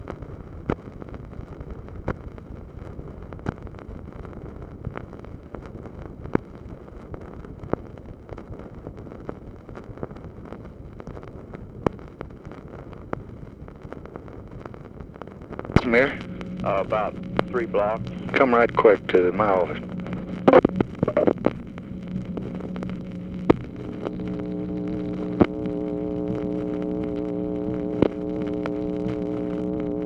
Conversation with HENRY FOWLER, March 18, 1965
Secret White House Tapes